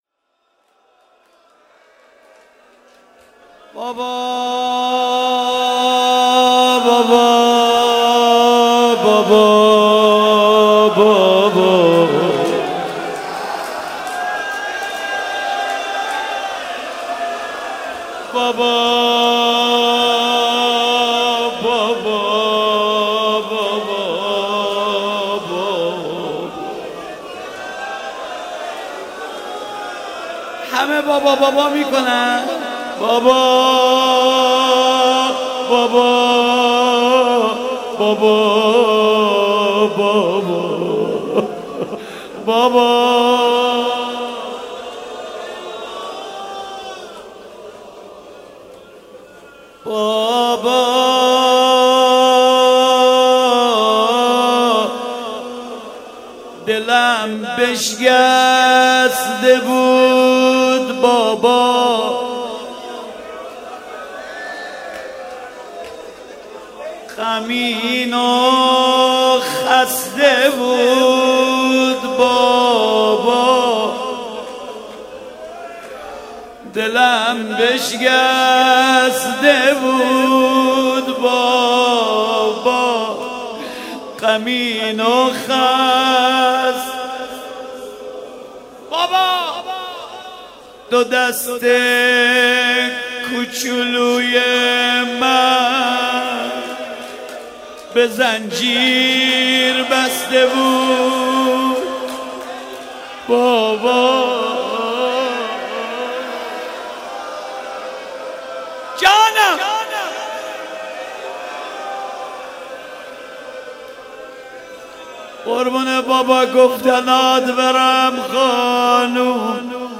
شب سوم محرم 95_روضه حضرت رقیه سلام الله علیها